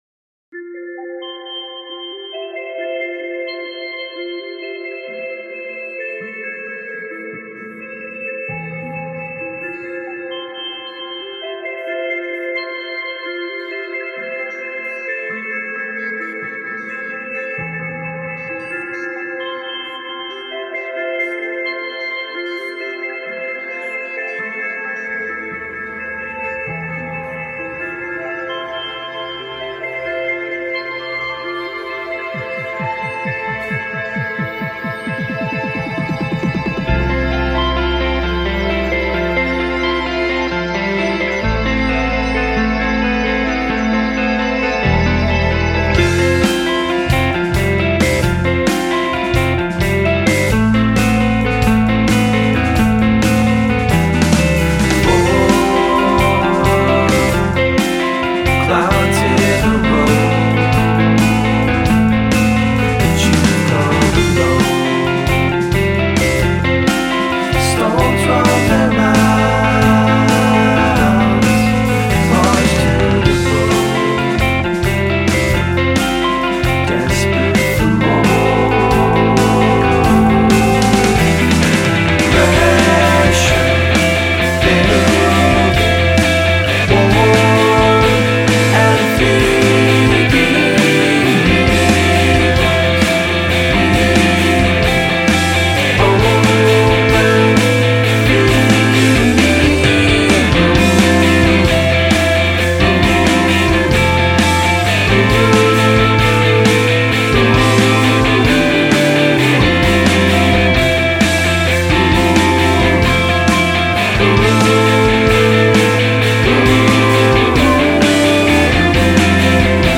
four-piece post-rock band